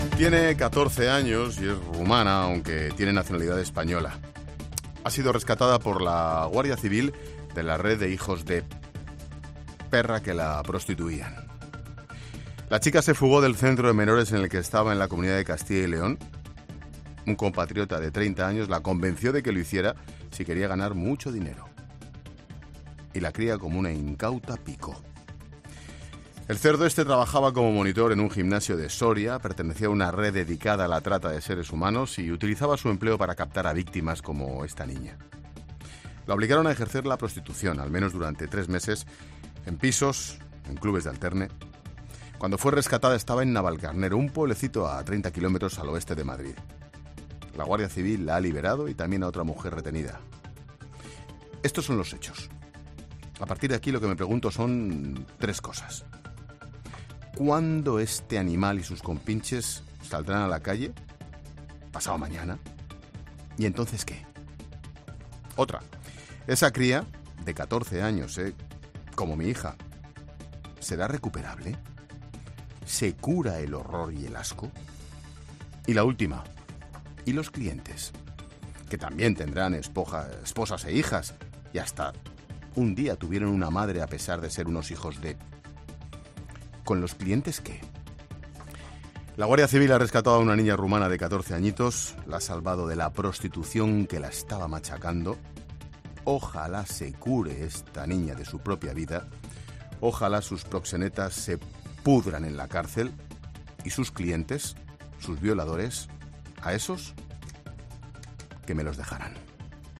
AUDIO: Monólogo 18h.